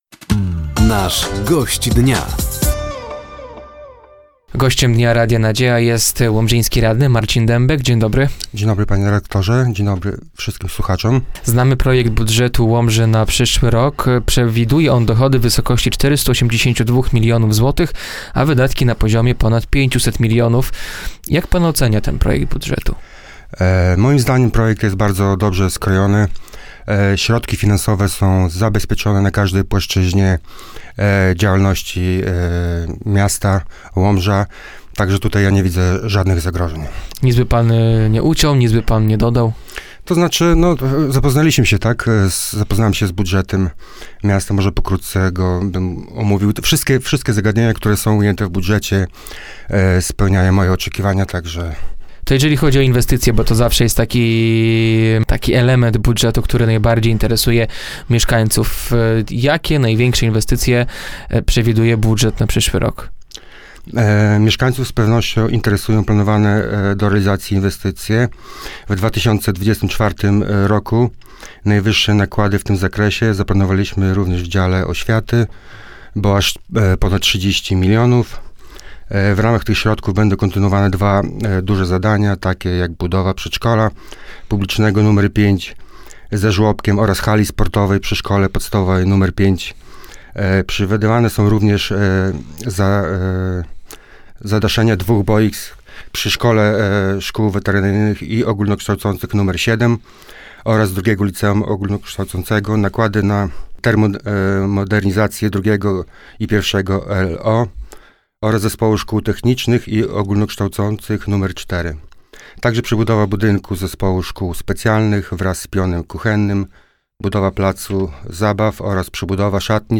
Gościem Dnia Radia Nadzieja był łomżyński radny, Marcin Dębek. Rozmowa dotyczyła projektu budżetu miasta na przyszły rok oraz pracy rady miejskiej.